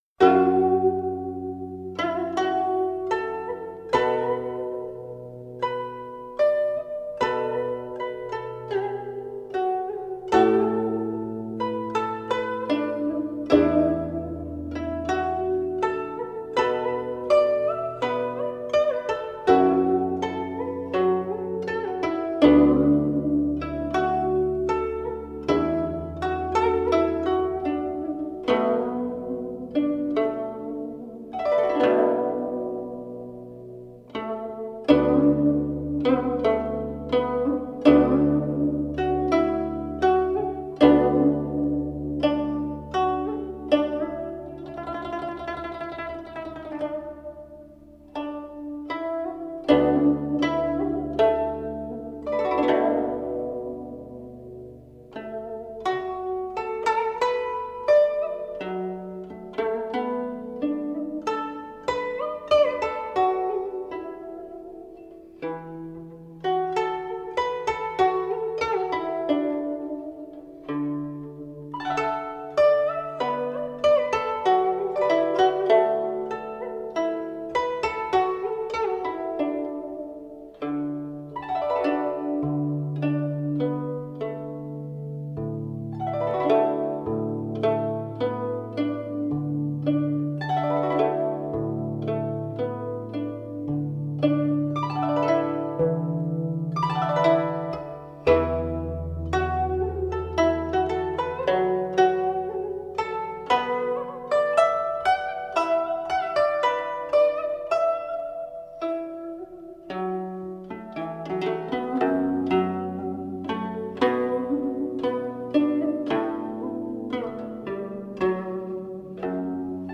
采用先进的数码录音技术